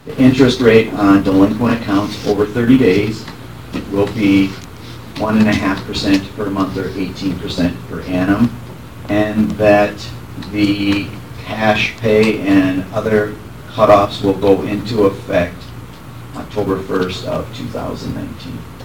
The next motion set the interest rate and when it goes into effect. State’s Attorney James Hare clarified this motion.